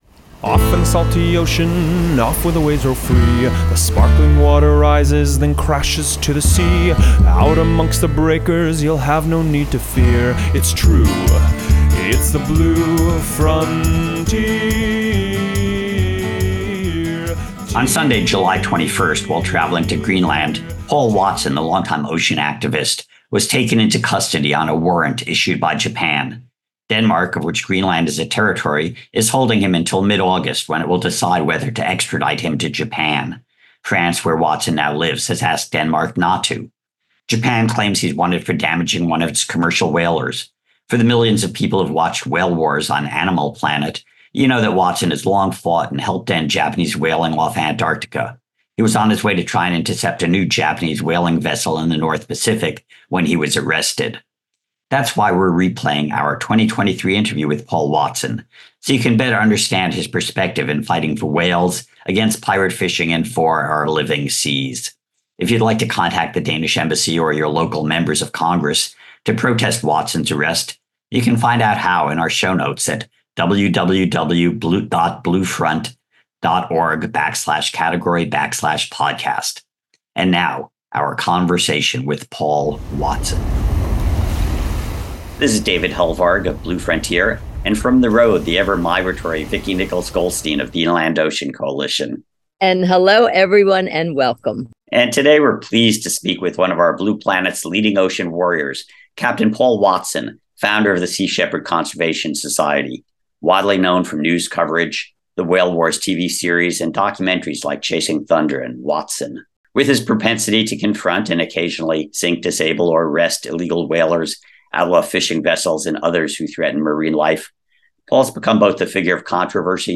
We’re replaying our October 2022 interview with Watson so you can better understand his perspective on fighting for whales, against pirate fishing, and for the health of the Ocean. In this episode, we talked to Captain Paul Watson, founder of the Sea Shepherd Conservation Society, about his background fighting for beavers, seals, and whales and the breakup with Sea Shepherd over its purpose and direction. We also discussed the Paul Watson Foundation, his biocentric religion, phytoplankton, and the planet, and how his time at the siege of Wounded Knee taught him not to fear for the future.